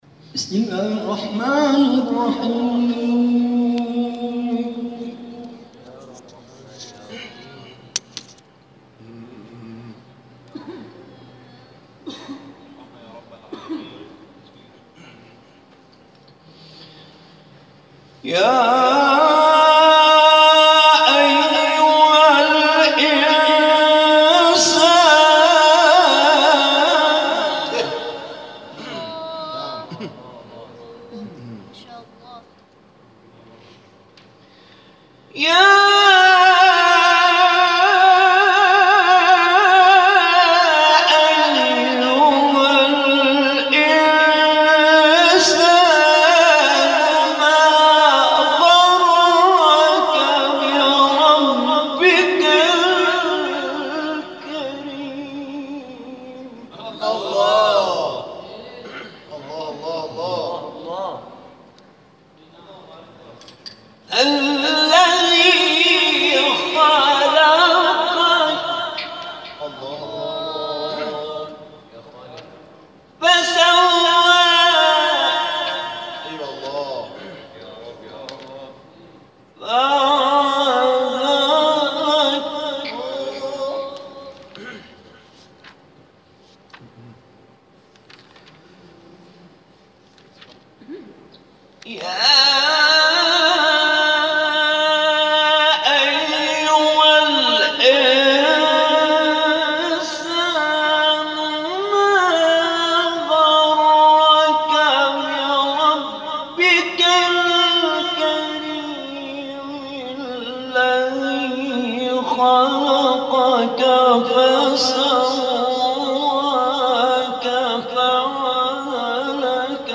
در ادامه تلاوت‌های این جلسه ارائه می‌شود.